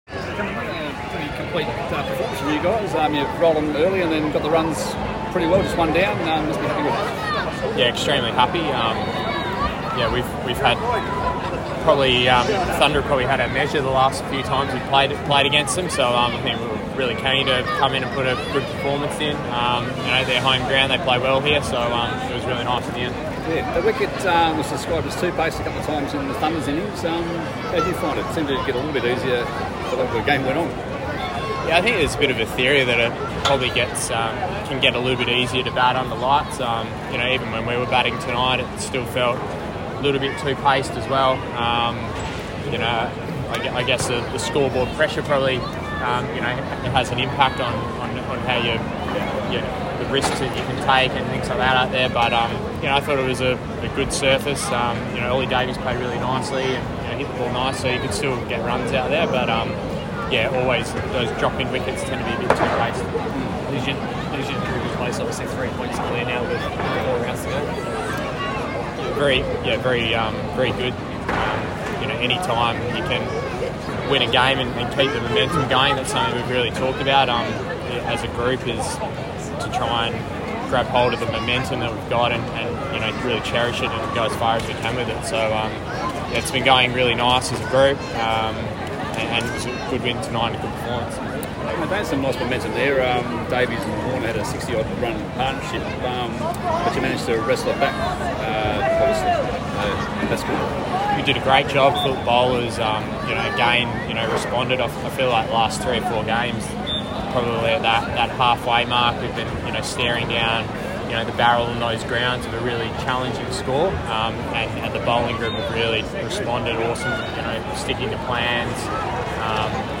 Scorchers opener Cam Bancroft spoke to the media after the Scorchers 9 wicket victory over the Sydney Thunder at the Sydney Showgrounds tonight.